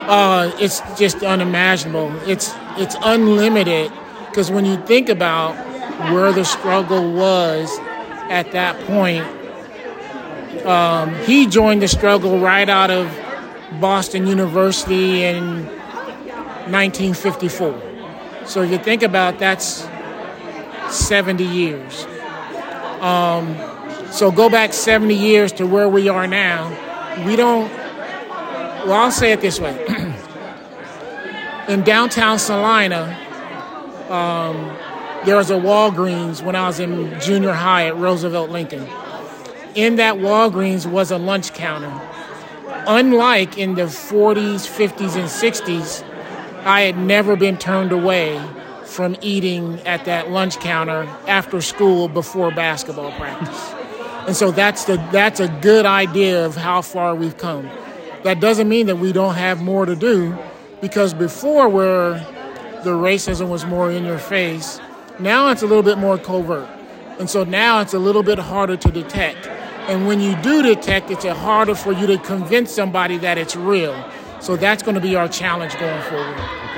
On Sunday afternoon, a crowd gathered at the Grand Avenue United Methodist Church in Salina, for an MLK dedicated event “Mission Possible: Protecting Freedom, Justice and Democracy.”